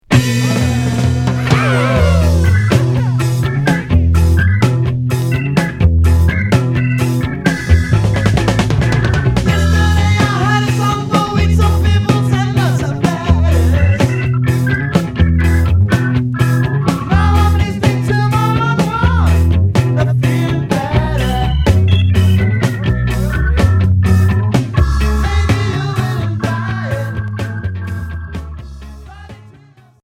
Pop progressif